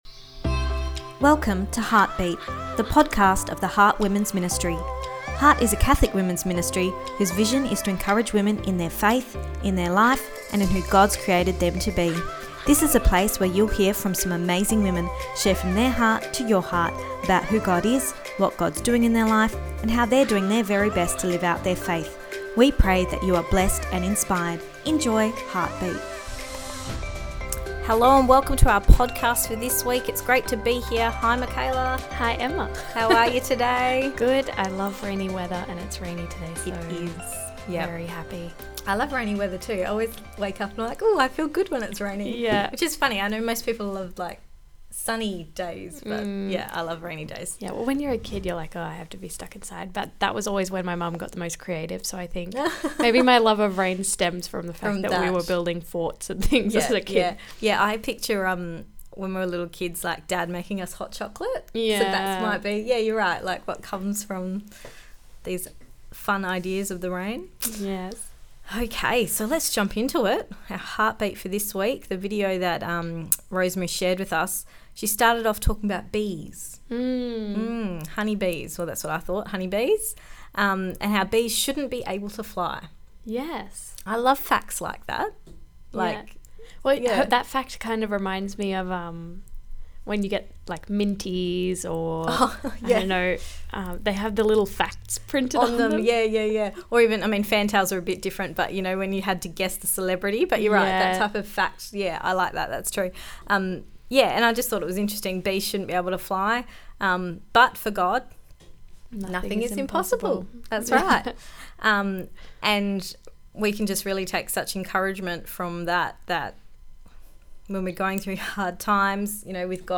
Episode 16 – God of the Impossible (Part 2 The Discussion)